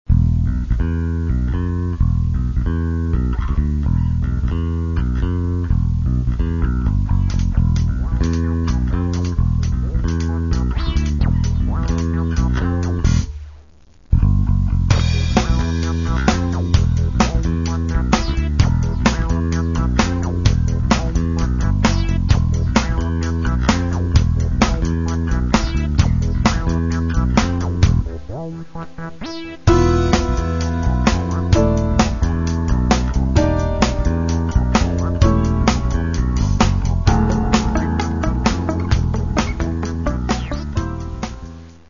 Каталог -> Джаз та навколо -> Міські суміші
фортепіано, гітара
ударні, перкусія